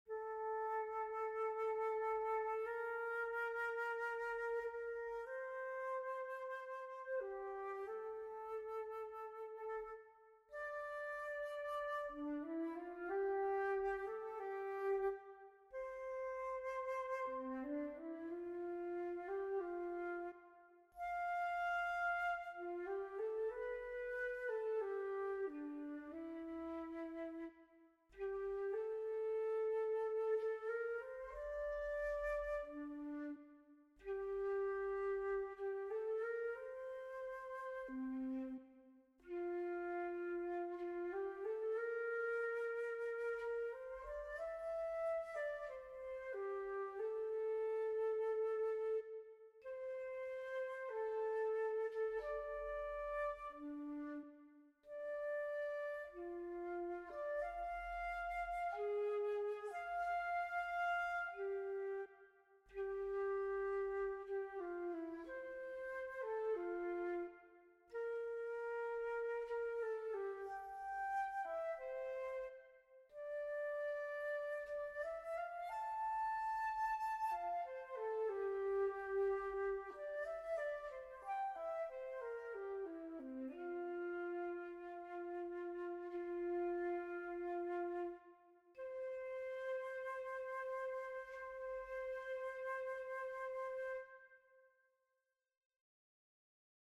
This arrangement is for solo flute.